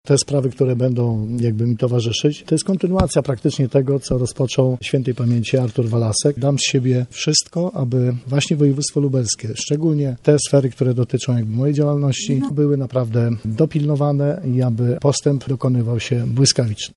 – Deklaruje, że dam z siebie wszystko – mówił chwilę po wyborze Grzegorz Kapusta, nowy wicemarszałek województwa.